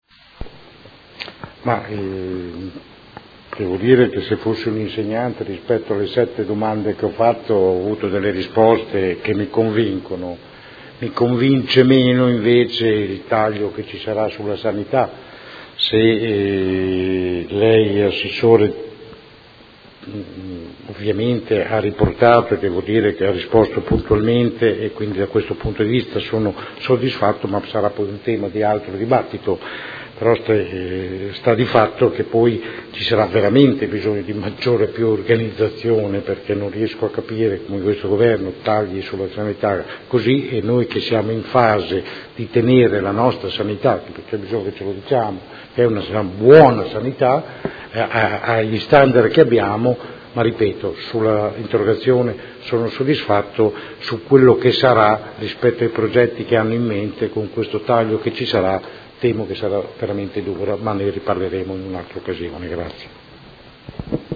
Seduta del 21/07/2016 Interrogazione del Consigliere Rocco (FAS-SI) avente per oggetto: Liste di attesa in materia sanitaria. Riferimento: attuazione Piano Regionale per ridurre le liste di attesa. Replica